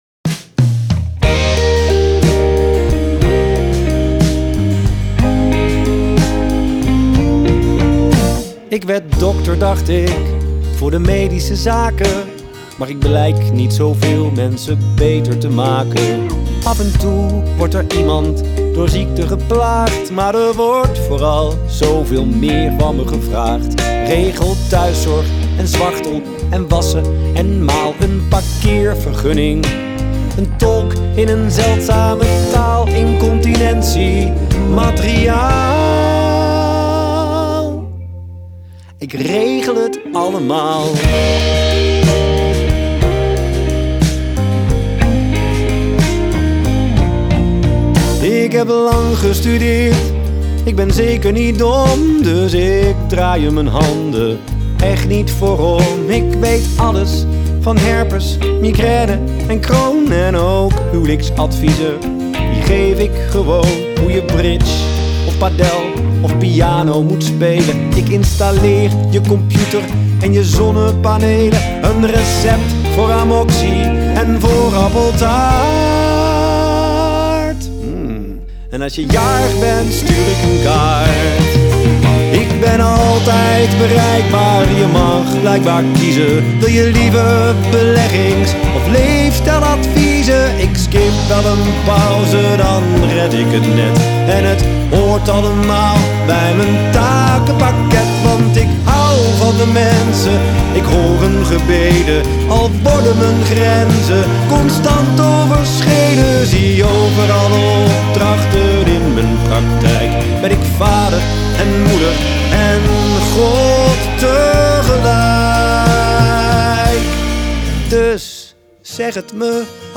Zang, gitaar
Gitaar
Bas, toetsen
Slagwerk